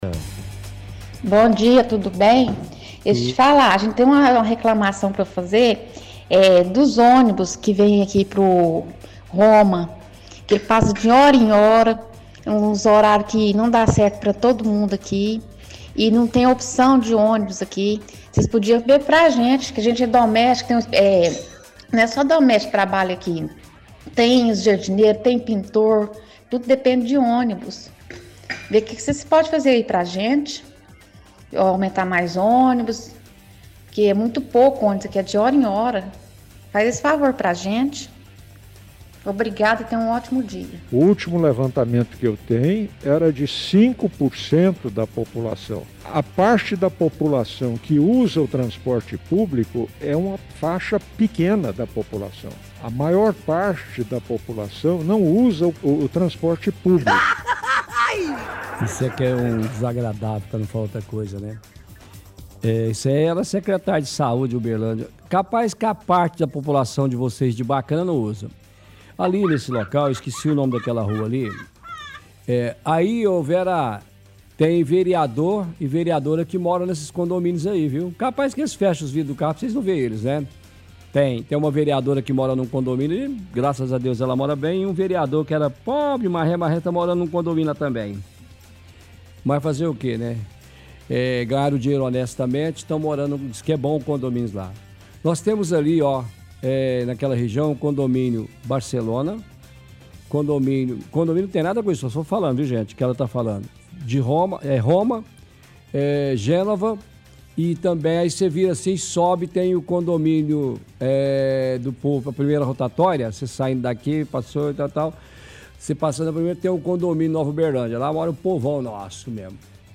Home / Rádio / Pinga fogo – Transporte público